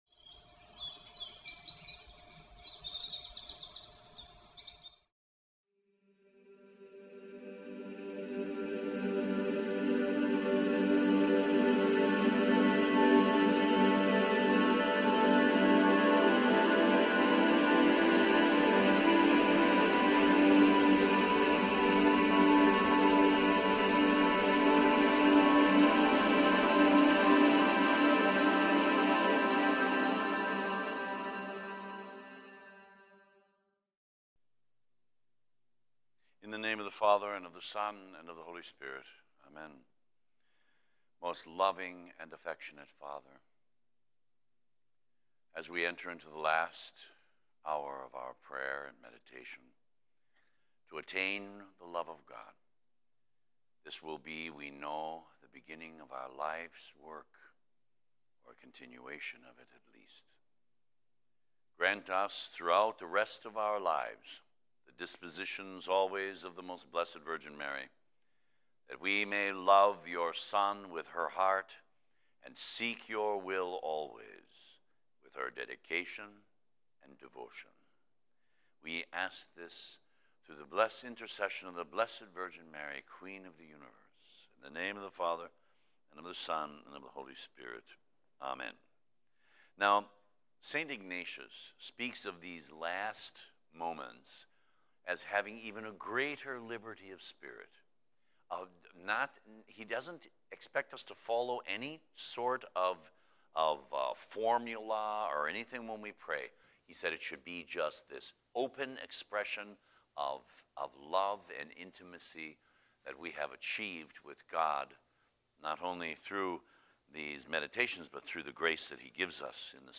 gives the last of twenty-five conferences for his Eight Day Ignatian Retreat. The subjects he covers are: What we must do to attain the love of God, prayer should be open expression of love. His sufferings to attain our love and our response.